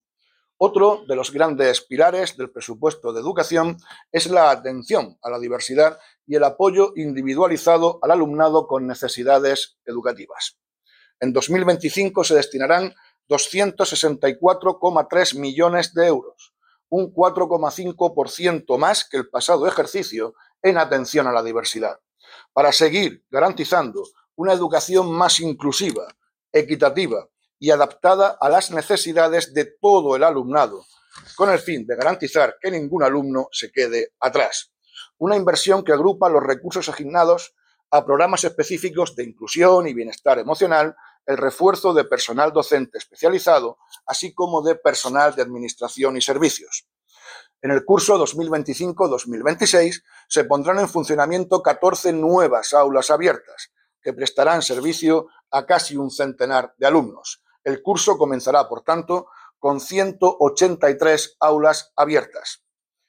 Abrir o Descargar archivo Declaraciones del consejero de Educación y Formación Profesional, Víctor Marín, sobre la inversión destinada a atención a la diversidad.